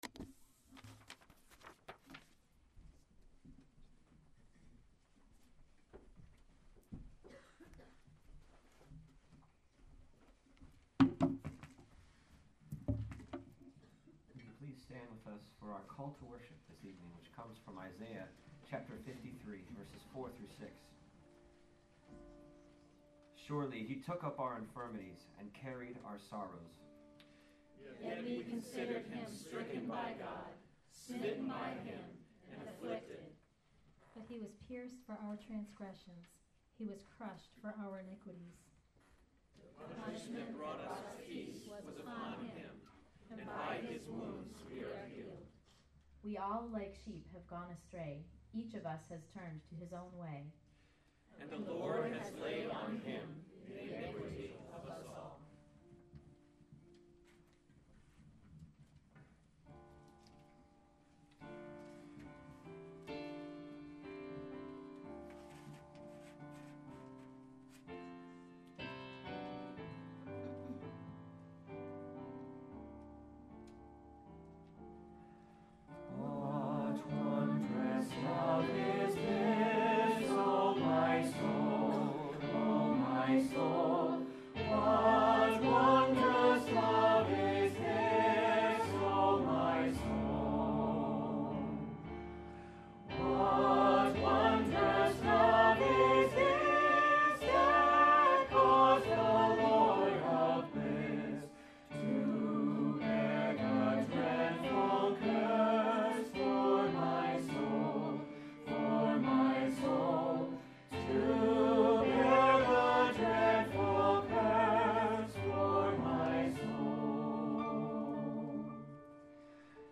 Good Friday Service (Part 1) • Church of the Redeemer Manchester New Hampshire